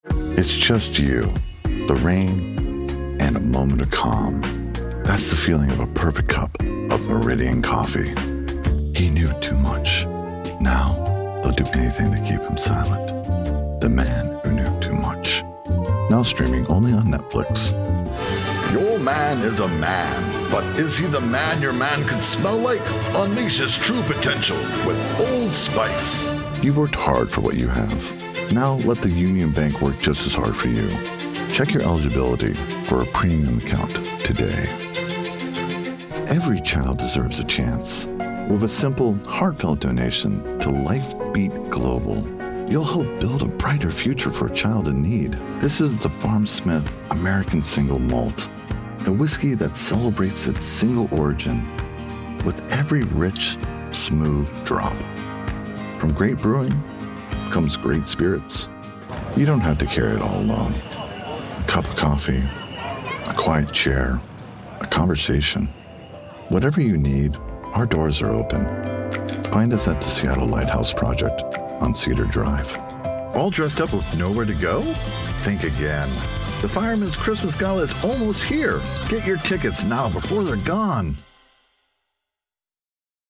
Deep, Calm tones delivering Confident Compassion
Commercial Demo Reel
Middle Aged